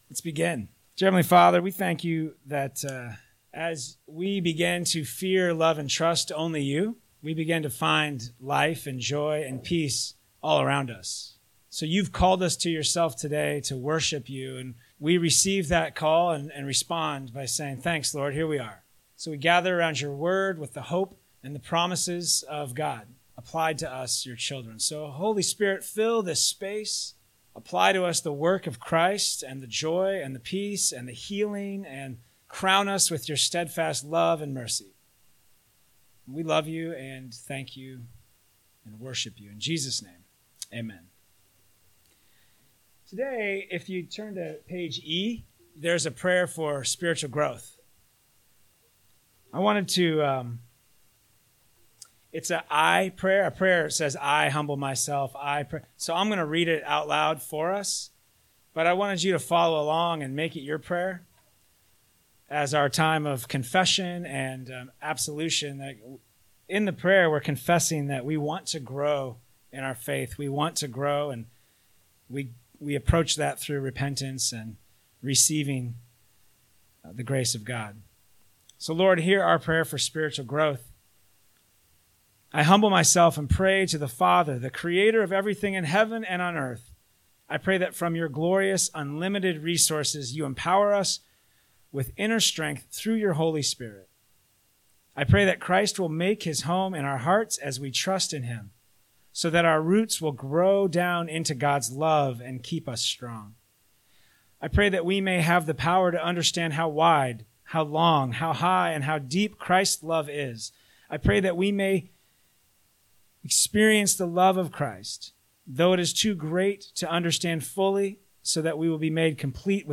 NCCO Sermons